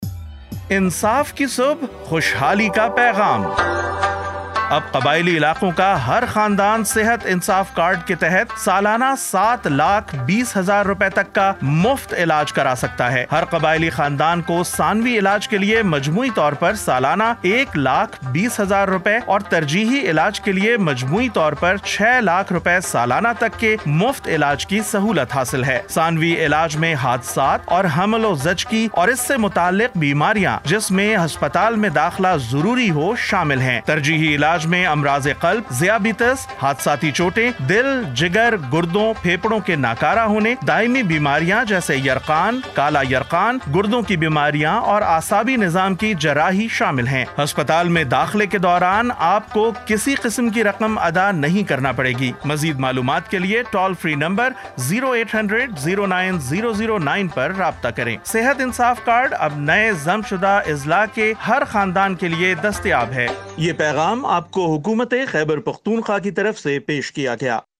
Urdu Radio Spot-2
Urdu-Radio-Spot-2.mp3